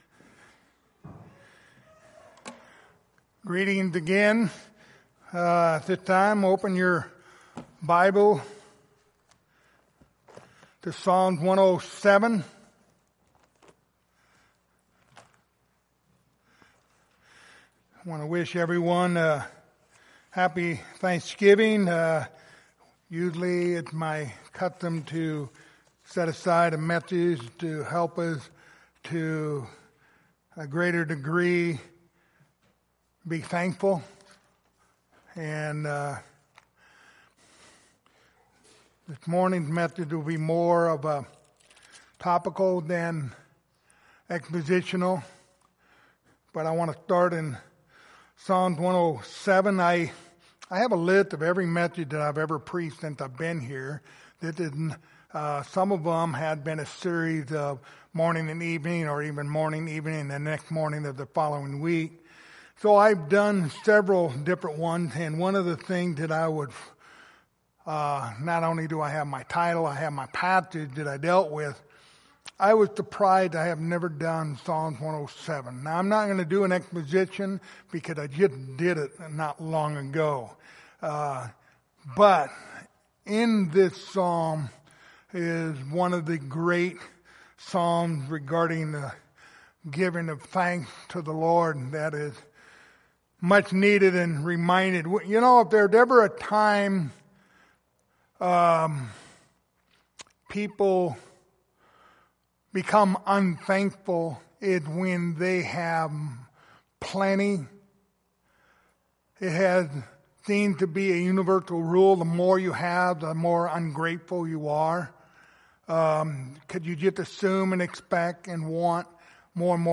Passage: Psalms 107:1-9 Service Type: Sunday Morning